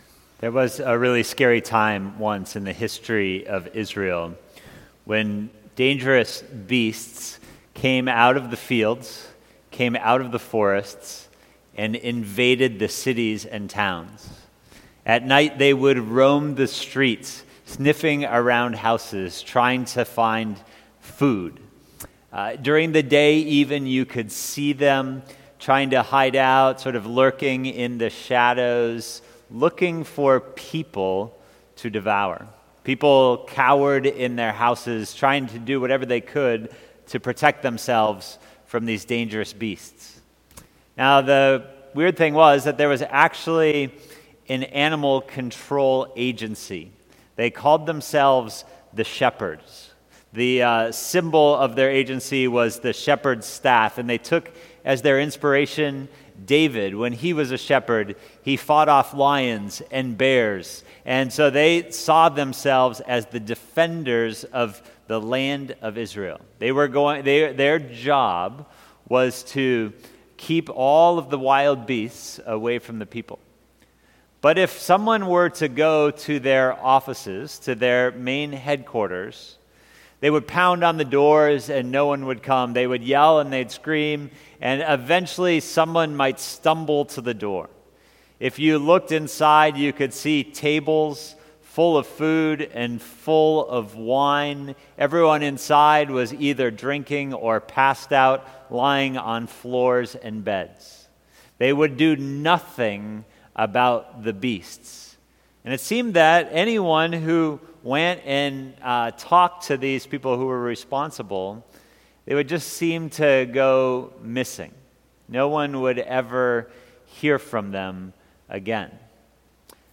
Isaiah 56:9-57:21. Sermon